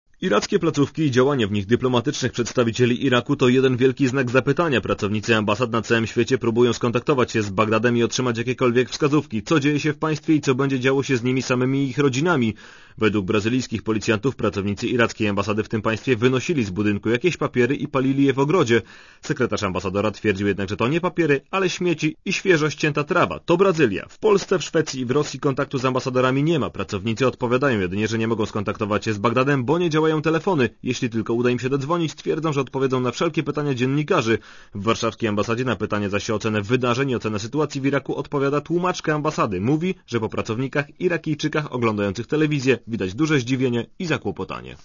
O irackich ambasadach w Polsce i na świecie reporter Radia Zet (174Kb)